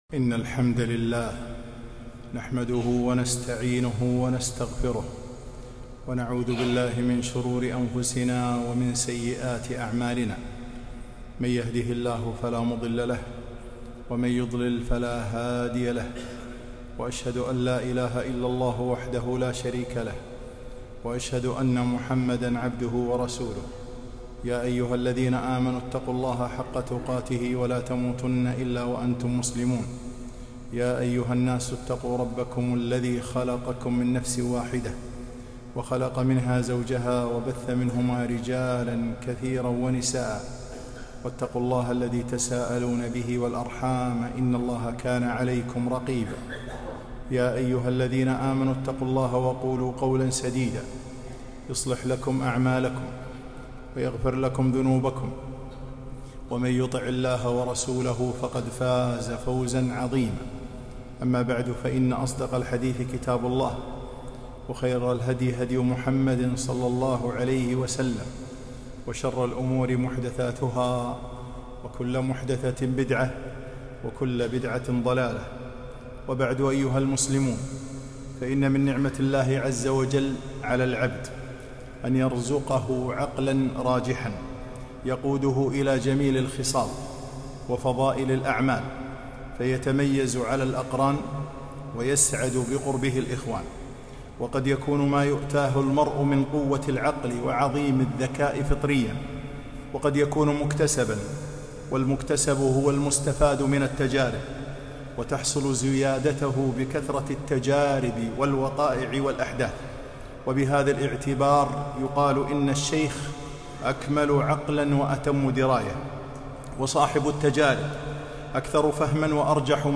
خطبة - العقلُ نعمة